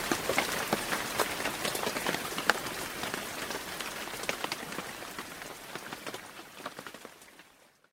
sleet.ogg